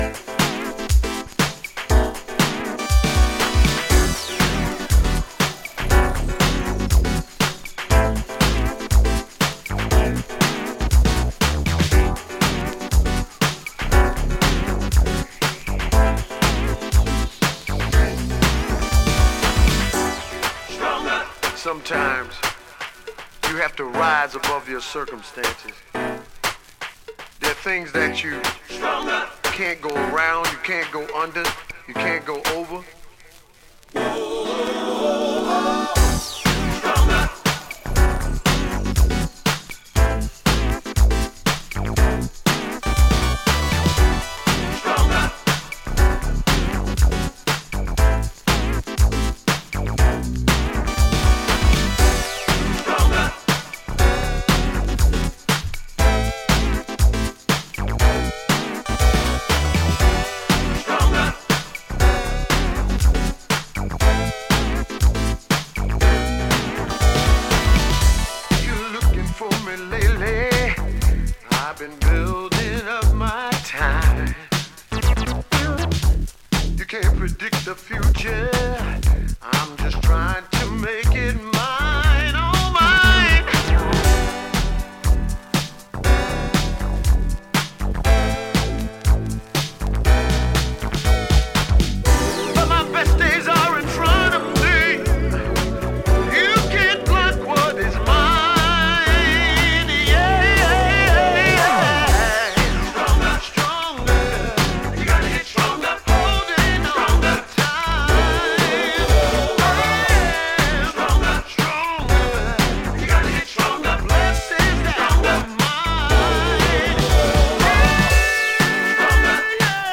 The soul, funk and disco 7″ community is a strong one.
a Danish and German duo
Boogie
is a positive and upbeat anthem